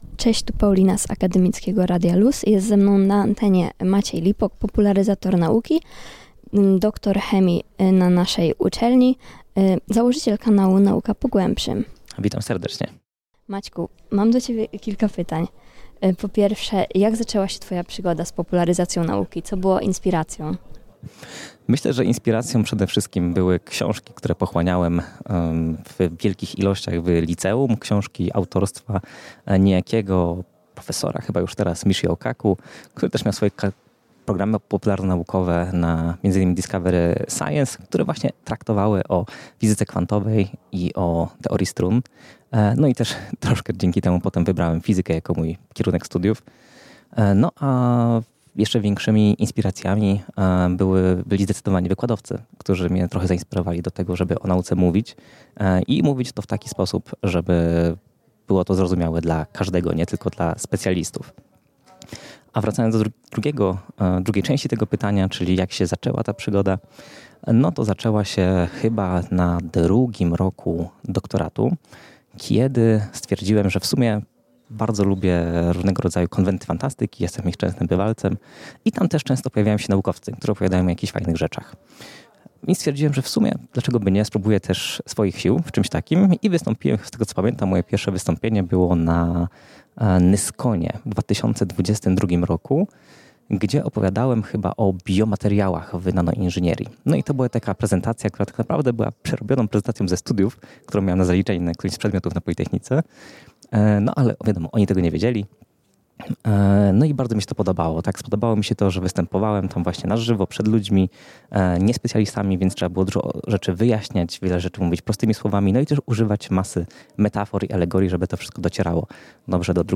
wywiad-v2.mp3